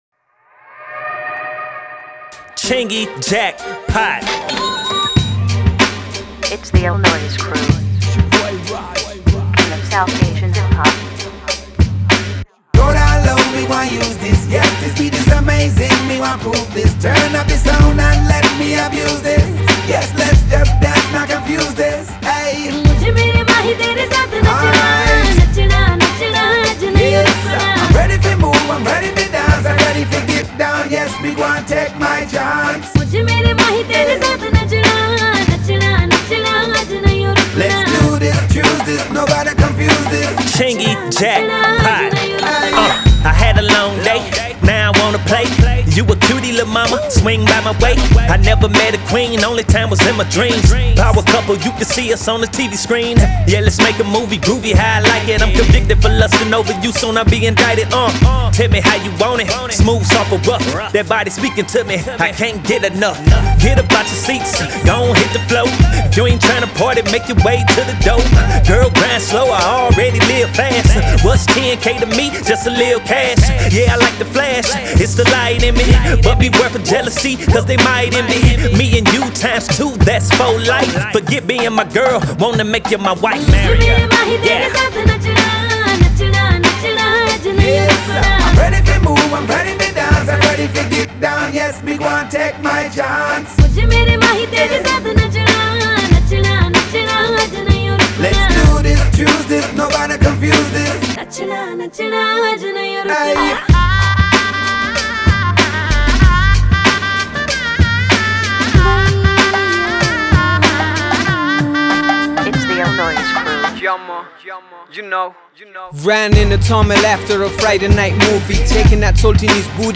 это зажигательный трек в жанре поп и хип-хоп